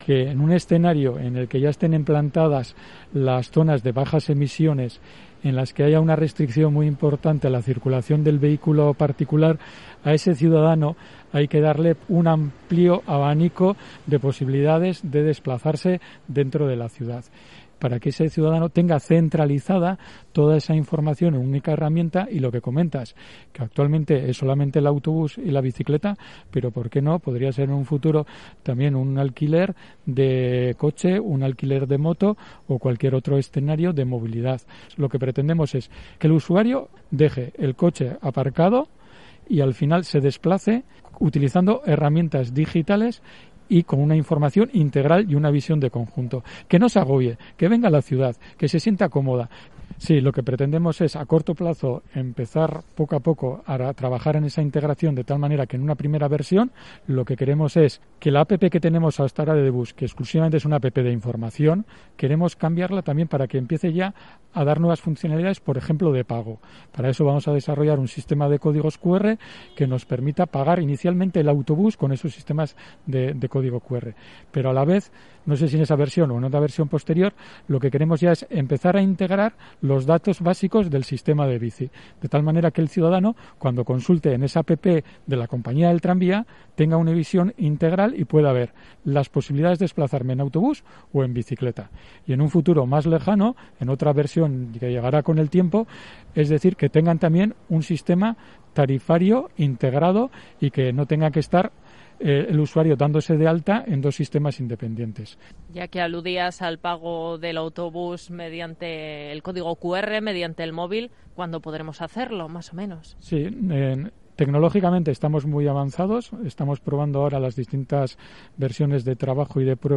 Entrevistado en Gipuzkoako Kale Nagusia de Onda Vasca